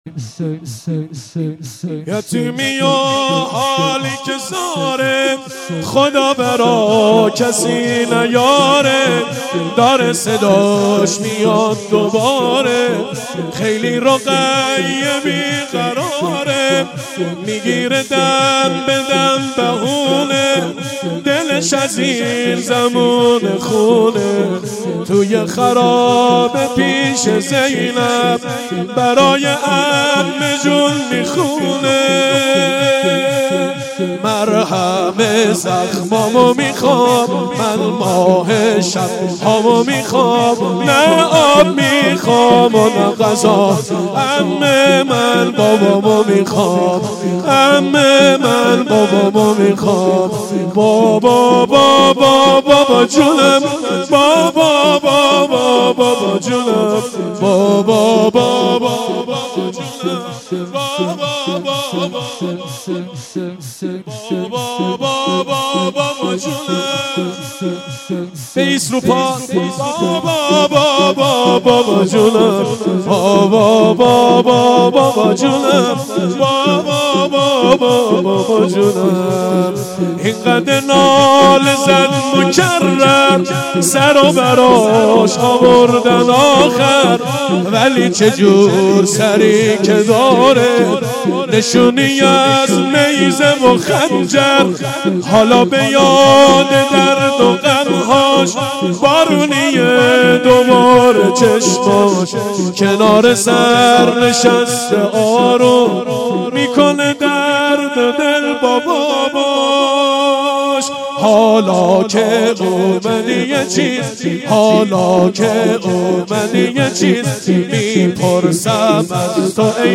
شور1